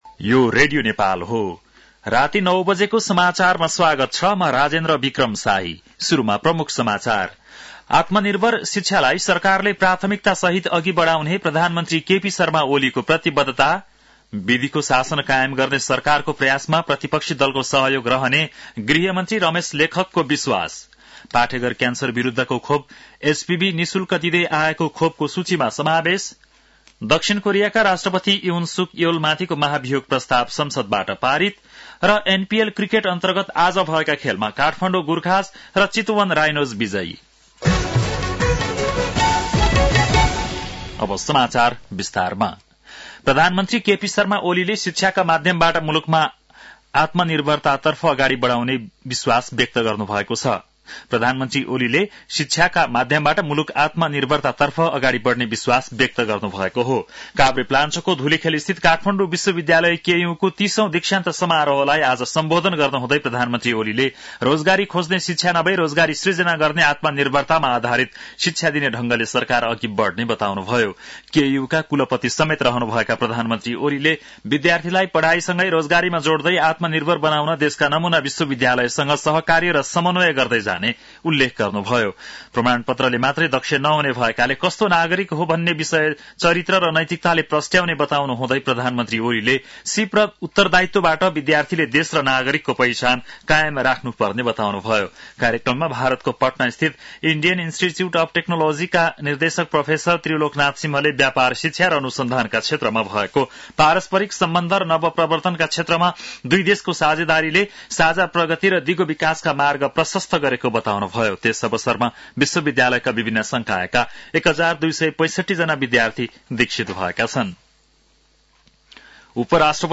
बेलुकी ९ बजेको नेपाली समाचार : ३० मंसिर , २०८१